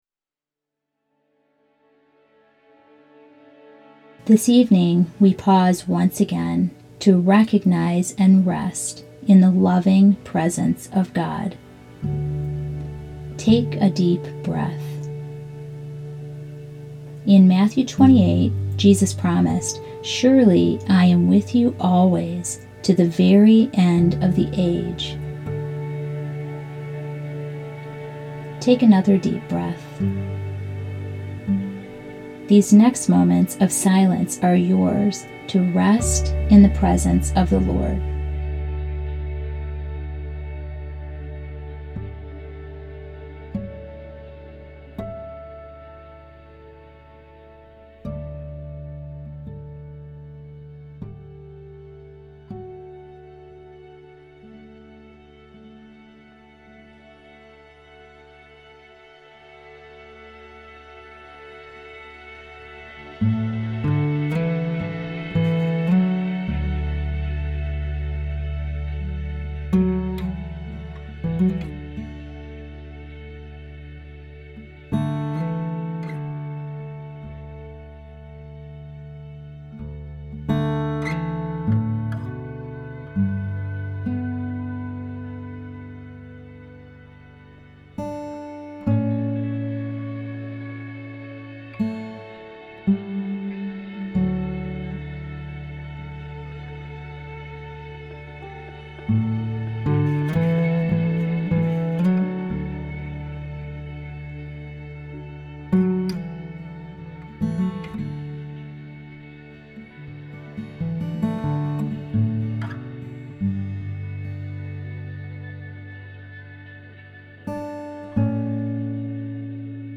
Below you will find a series of guided prayers.
Each prayer begins in silence.